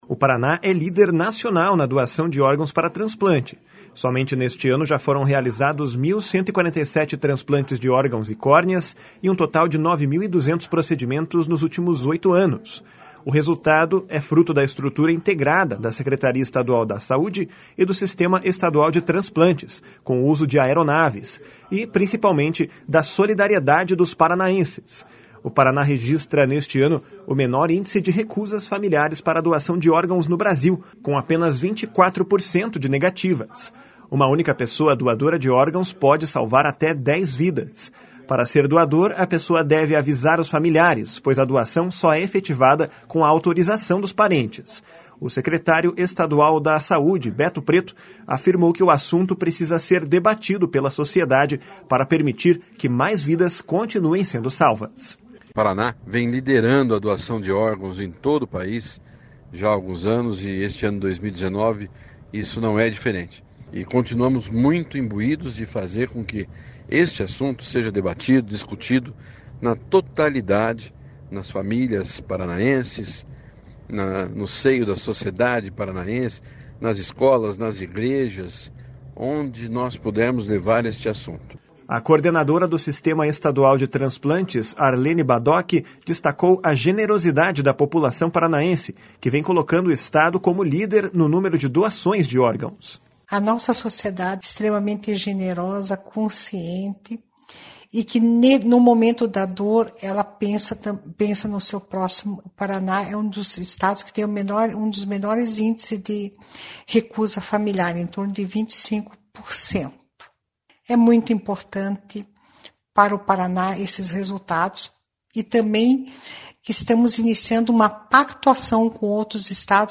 O secretário estadual da Saúde, Beto Preto, afirmou que o assunto precisa ser debatido pela sociedade para permitir que mais vidas continuem sendo salvas. // SONORA BETO PRETO //